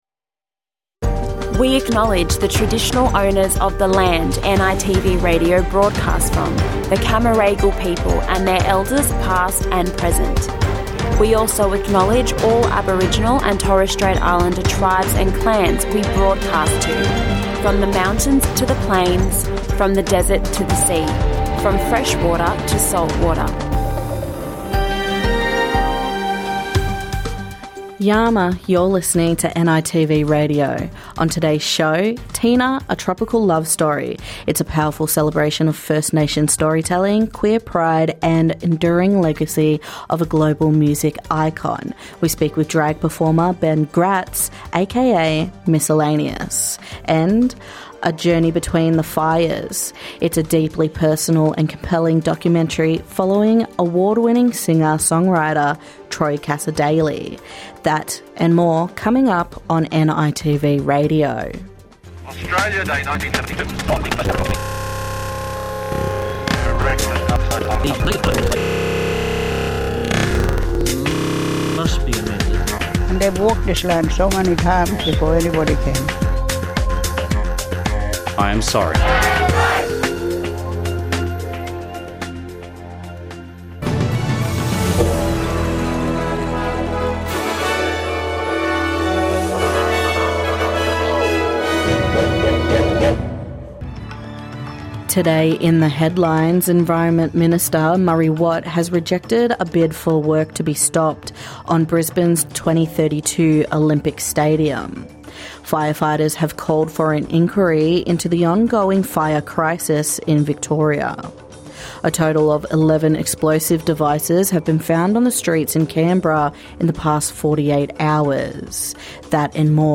And a deep conversation with acclaimed singer and songwriter Troy Cassar-Daley in a documentary that follows him in the throws of grief and creativity.